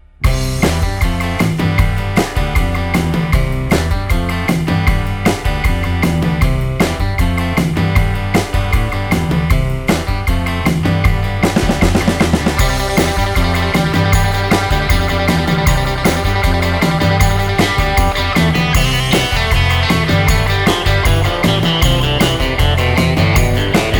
Minus Lead And Solo Rock 'n' Roll 2:25 Buy £1.50